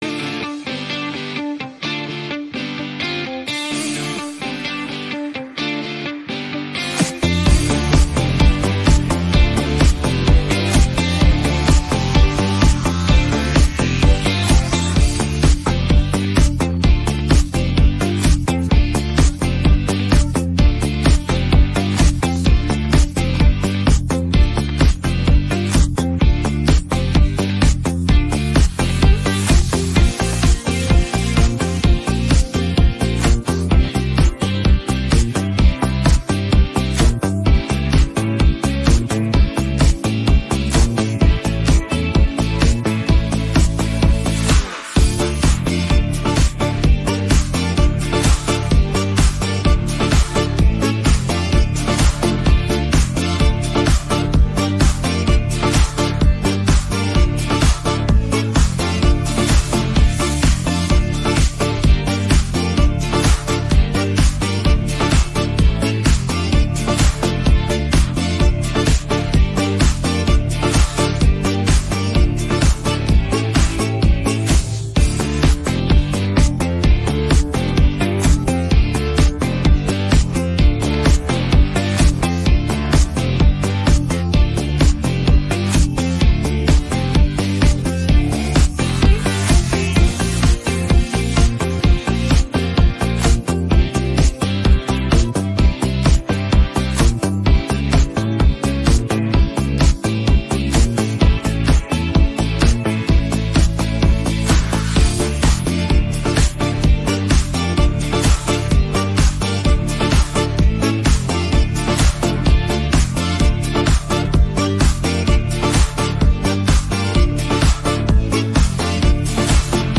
запальна пісня для справжнього вечора!